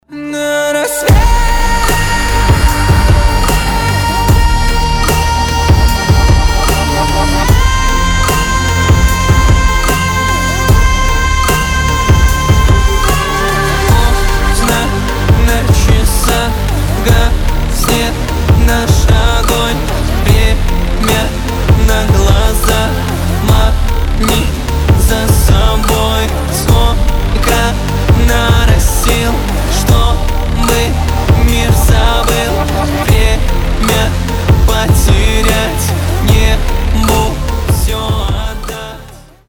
• Качество: 320, Stereo
громкие
мощные
красивый мужской голос
этнические
красивый вокал
сильный голос